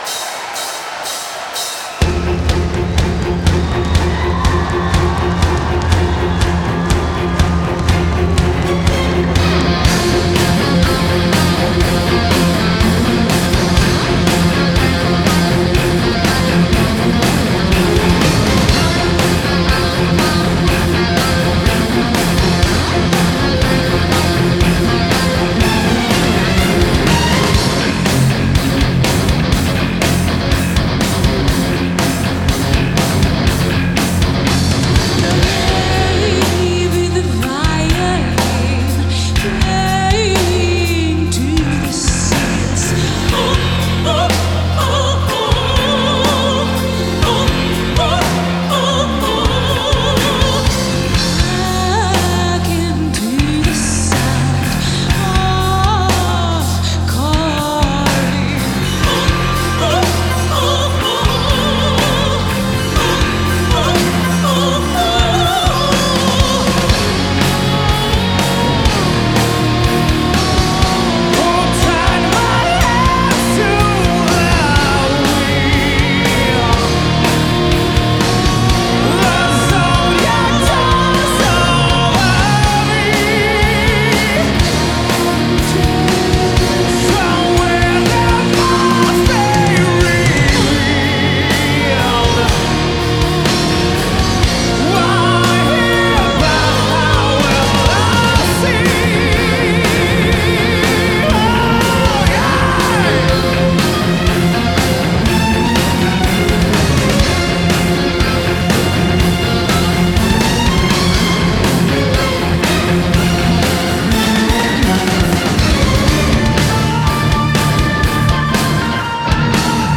Genre: Symphonic Rock